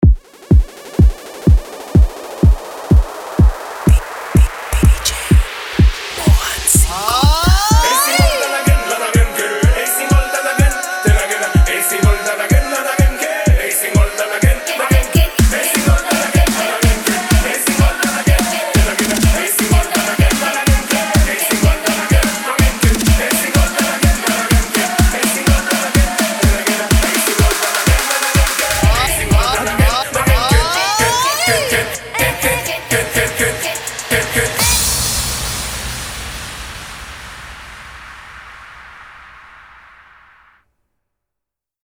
קריינות לסט (חבילה 5 משפטים) - יקיר כהן הפקות: אולפן הקלטות במודיעין – קריינות ואטרקציות לאירועים 2025 במחירים גלויים!
קריינות לסט (חבילה 5 משפטים) – הזמינו קריינות מקצועית לסט שלכם ותיהנו מחוויה בלתי נשכחת שתרים אתכם ואת הקהל לגבהים חדשים!